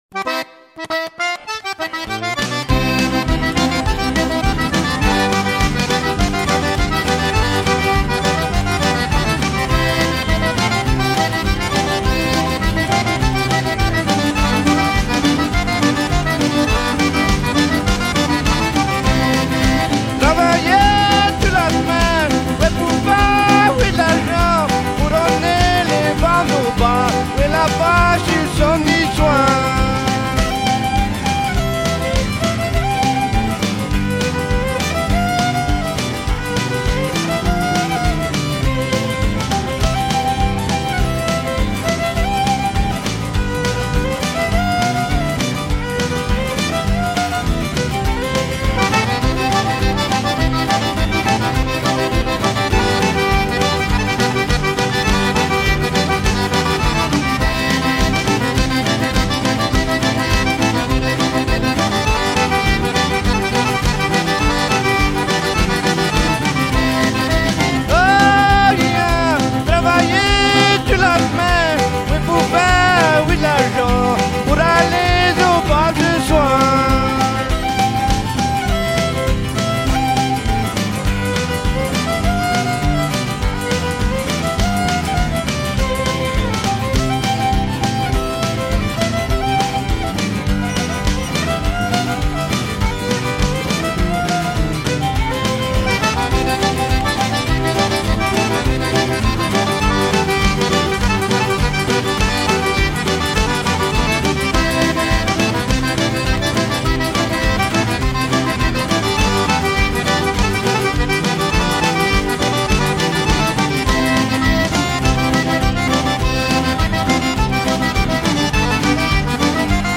Cajun fiddle